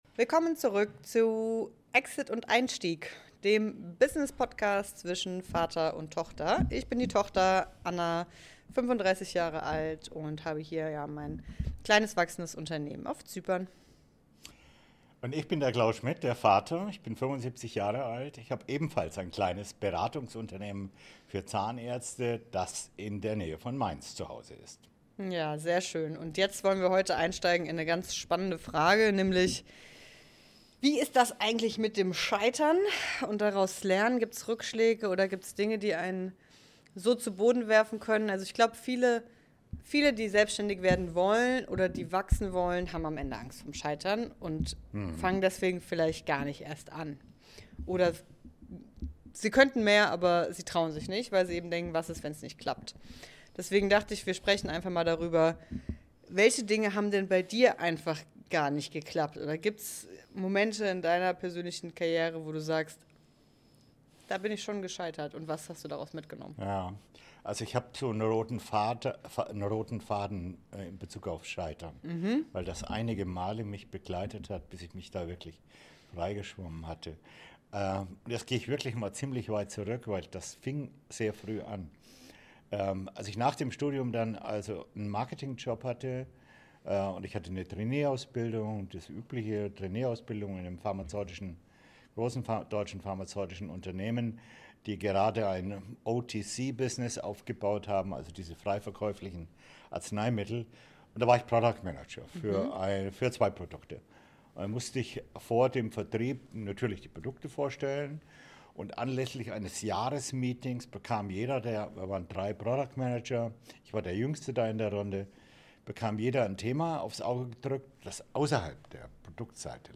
Es geht um Verantwortung, Ego, Struktur und darum, wie man Wissen, Führung und Entscheidungen so verteilt, dass etwas bleibt, das größer ist als man selbst. Ein ehrliches Gespräch über Nachfolge, Skalierung und die Frage: Wann ist ein Unternehmen wirklich gut gebaut?